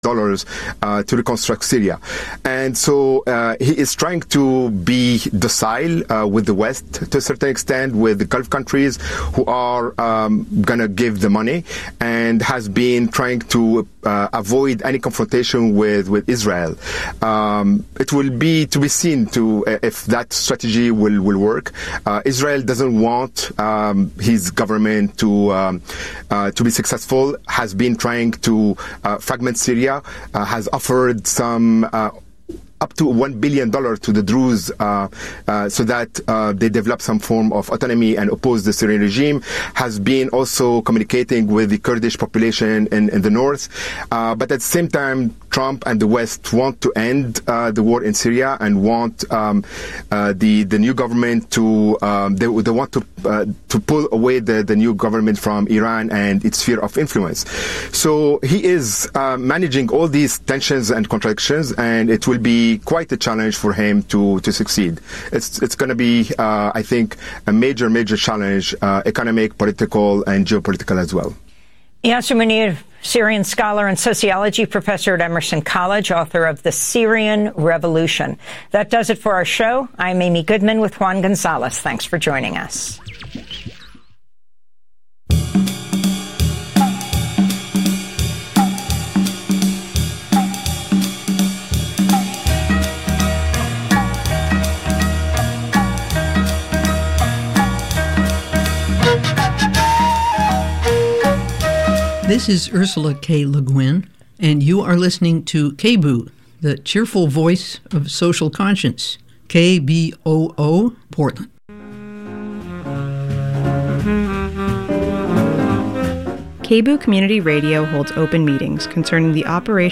Non-corporate, community-powered, local, national and international news